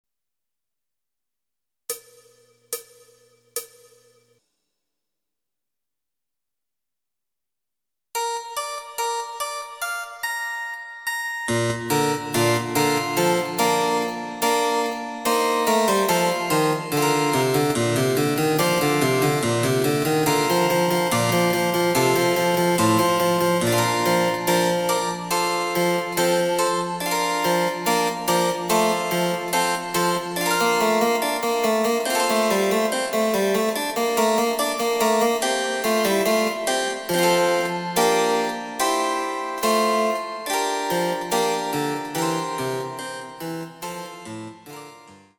その他の伴奏
第２楽章　極端に遅い
Electoric Harpsichord